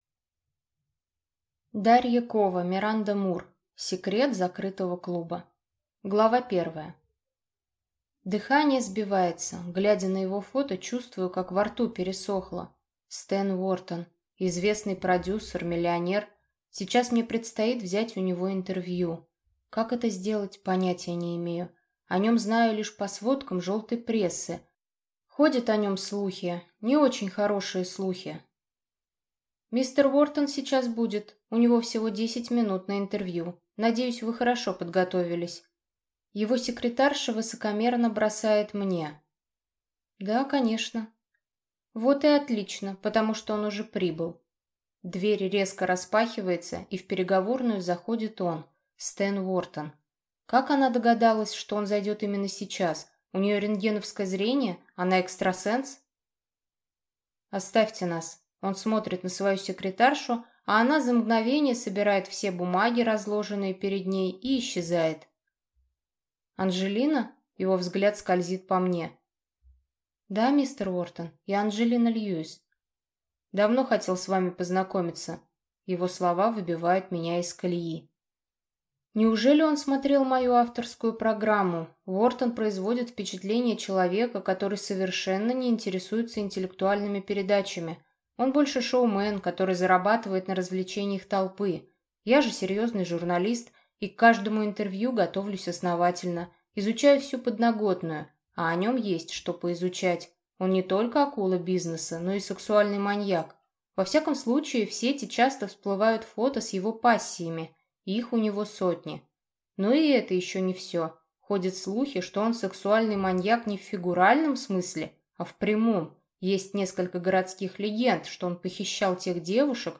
Аудиокнига Секрет закрытого клуба | Библиотека аудиокниг